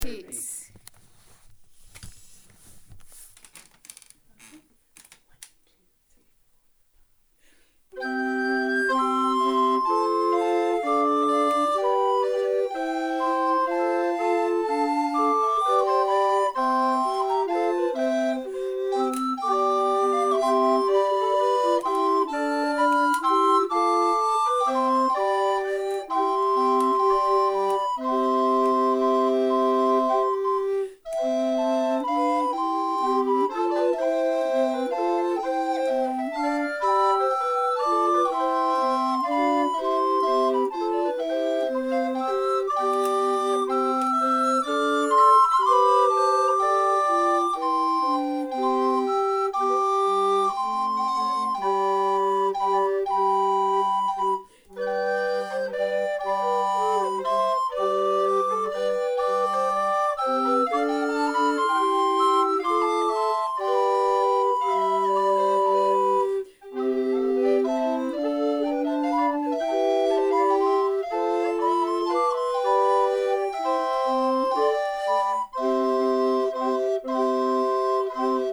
From our Renaissance repertoire